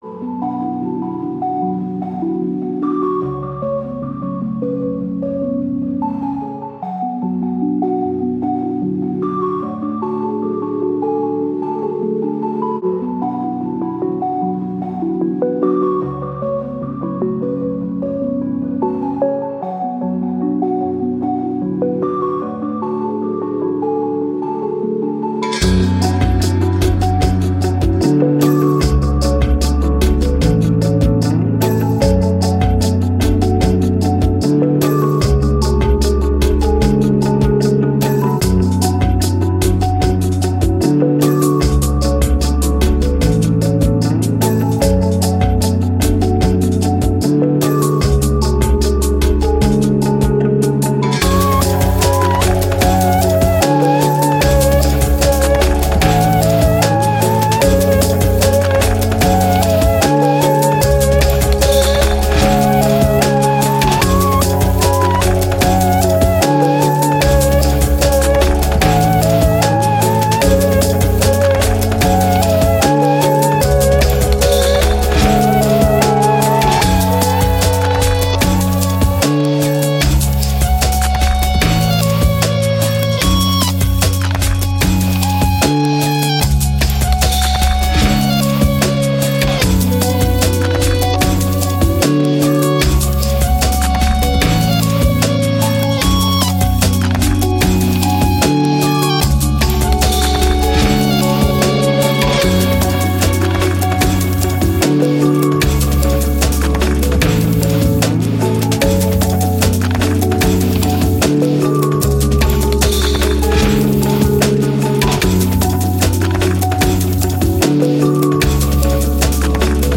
Electronic, Glitch, IDM, Reverse, Tense, Pensive